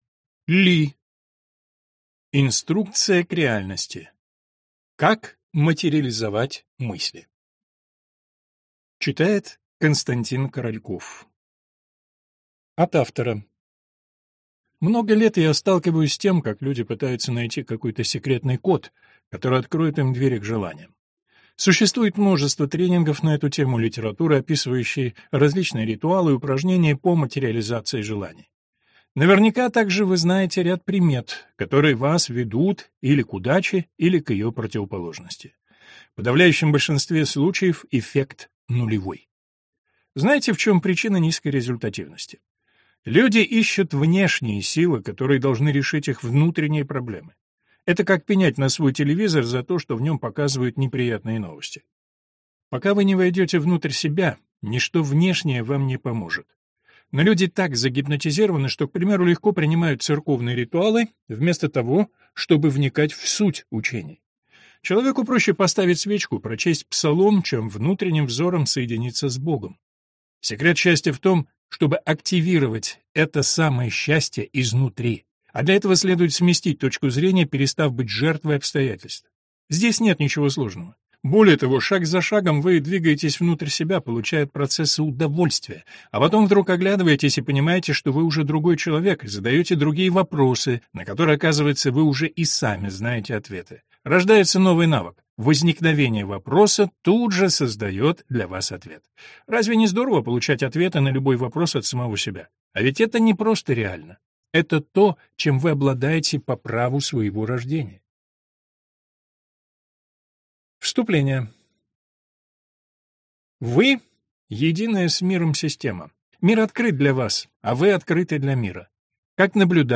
Аудиокнига Как материализовать мысли. Инструкция к реальности | Библиотека аудиокниг